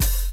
• '00s Dark Rap Open Hi Hat Sample F# Key 01.wav
Royality free open hi hat sample tuned to the F# note. Loudest frequency: 3431Hz
00s-dark-rap-open-hi-hat-sample-f-sharp-key-01-uHF.wav